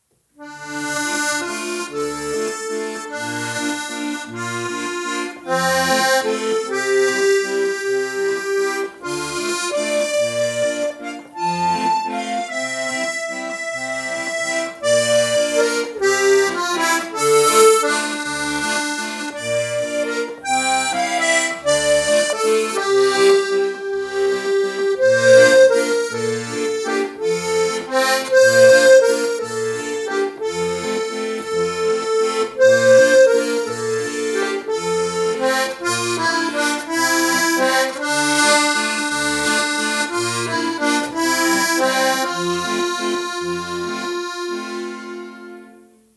CF Akkorde.mp3